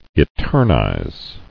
[e·ter·nize]